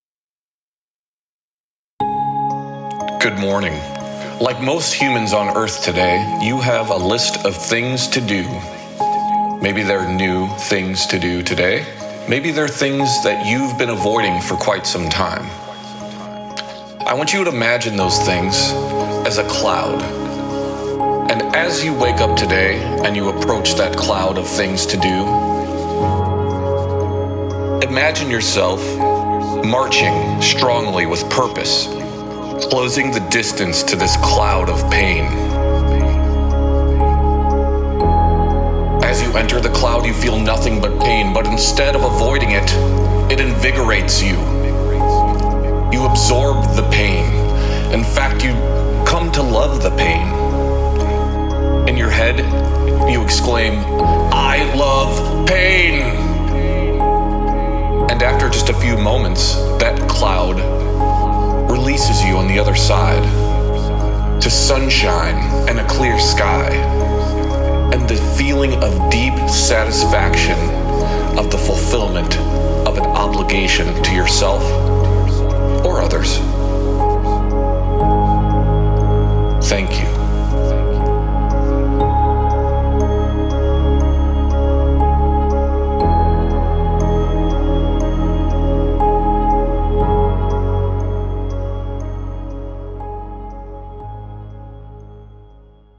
Once recorded, our musicians infuse the mini with unique, powerful music, capturing its essence.